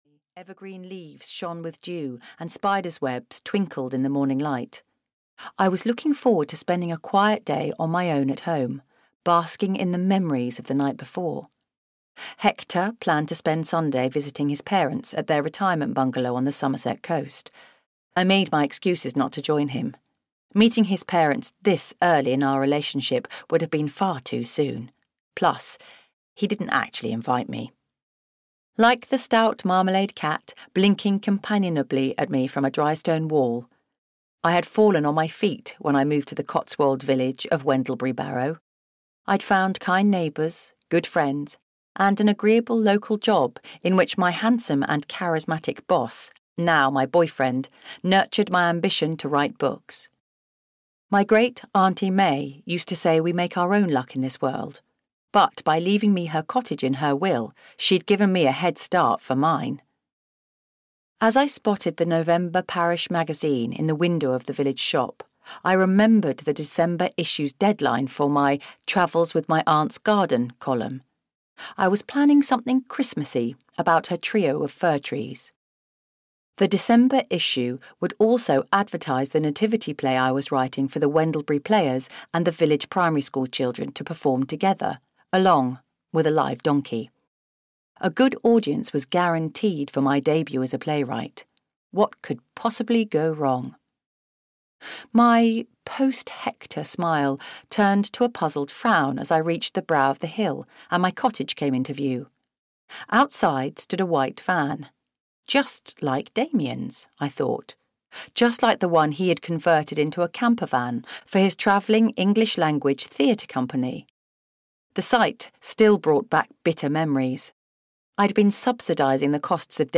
Murder in the Manger (EN) audiokniha
Ukázka z knihy